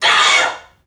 NPC_Creatures_Vocalisations_Robothead [17].wav